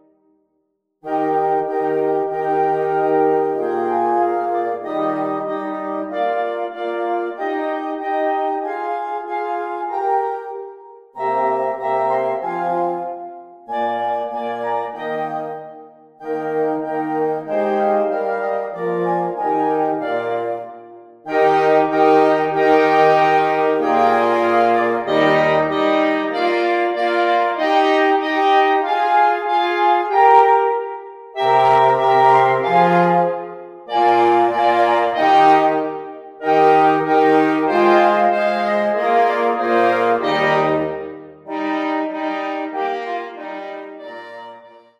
2 clarinets, 2 horns, 2 bassoons
(Audio generated by Sibelius/NotePerformer)